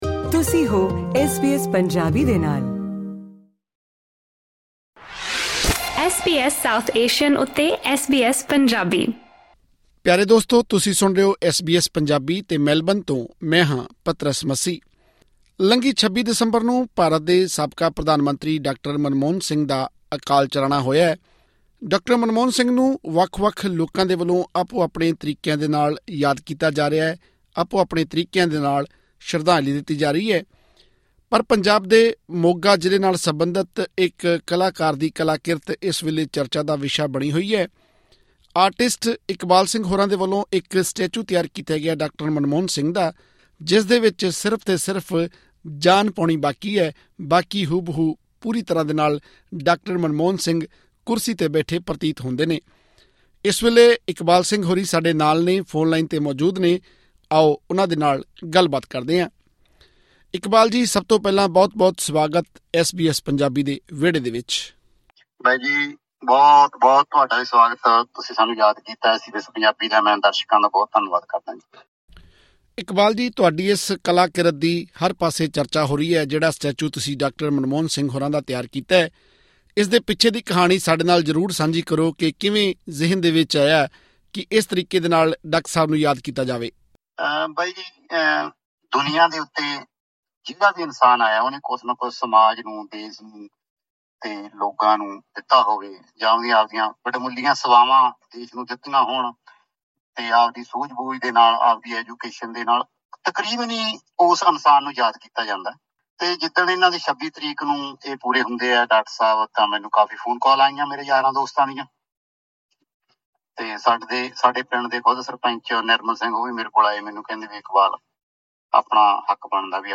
ਹੋਰ ਵੇਰਵੇ ਲਈ ਸੁਣੋ ਇਹ ਗੱਲਬਾਤ…